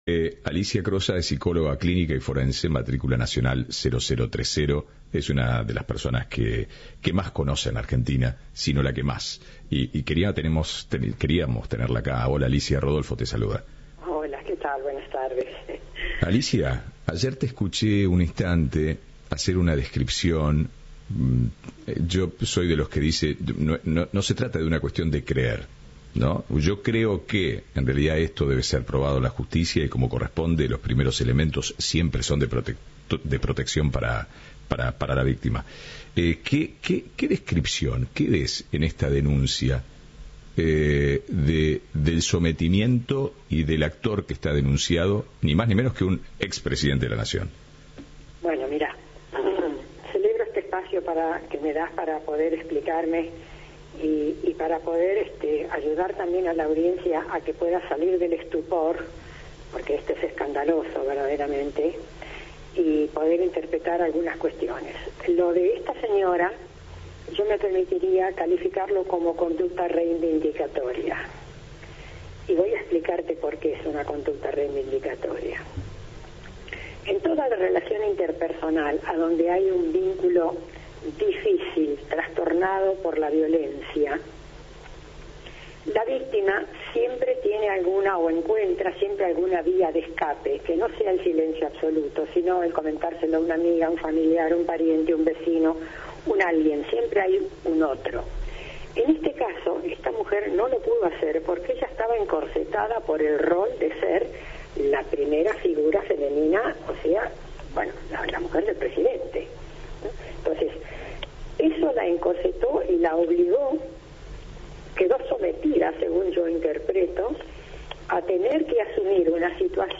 Entrevista de "Ahora País".